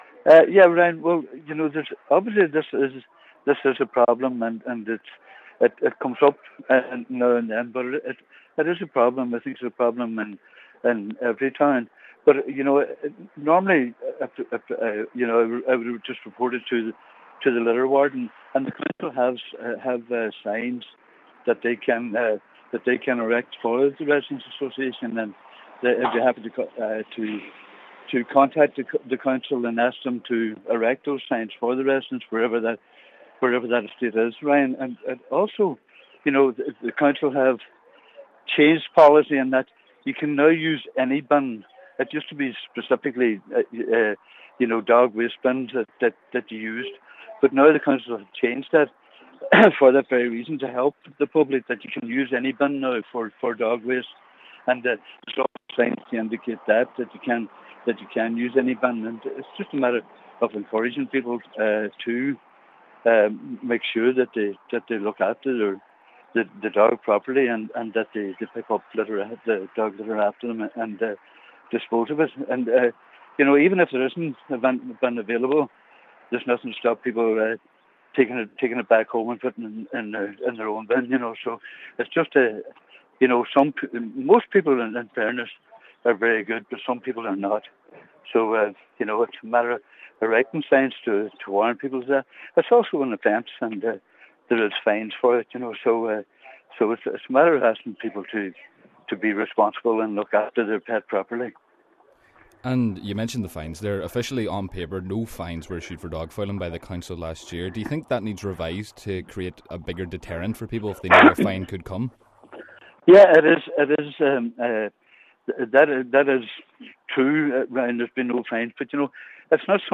Cllr Jimmy Kavanagh says the council have made it easier for owners to pick up after their dogs: